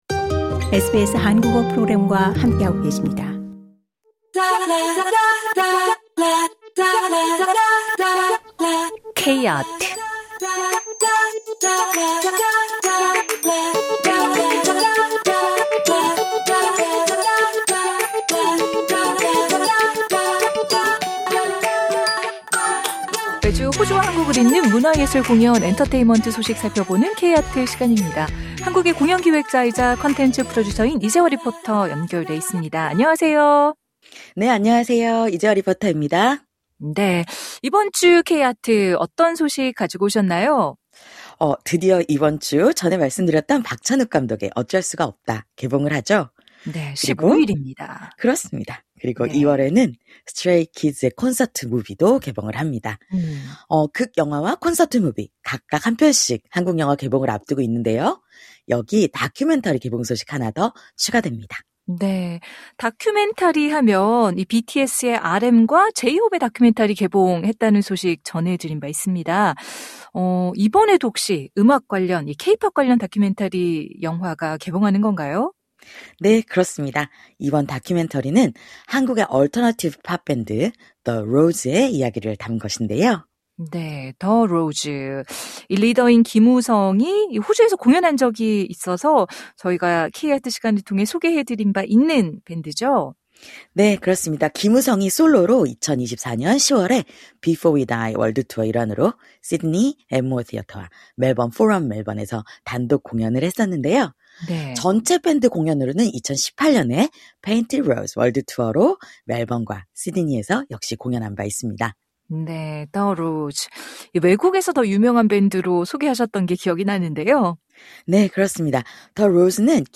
리포터와 함께합니다.